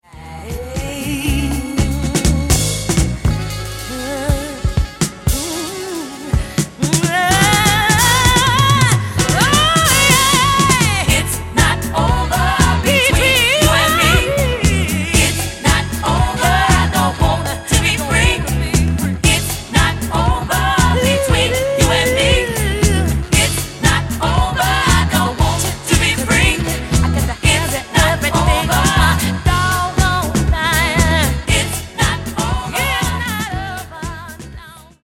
Genere:   Disco | Funky | Soul |